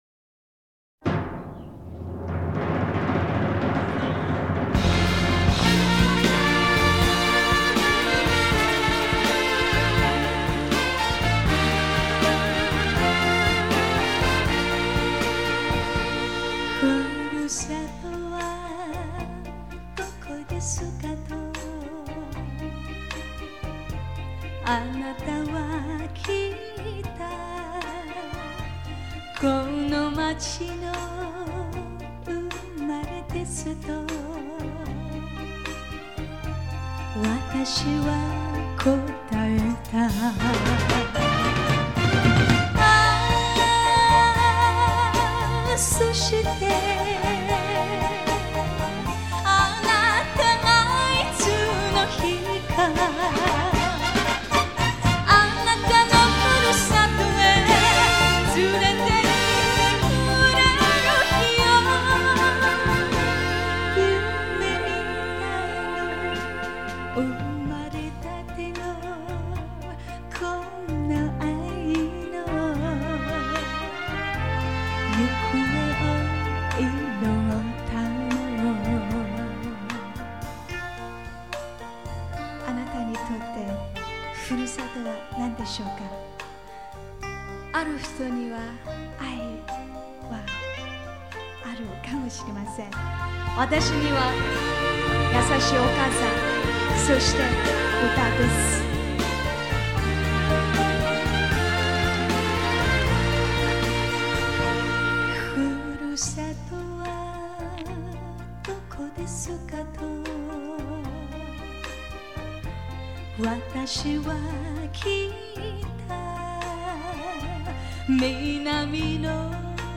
もちろん歌の色香，しなやかさは完成されているが，日本での本格的リサイタルということもあって緊張感は多い。
STEREO MADE IN JAPAN
新橋·ヤクルトホールにて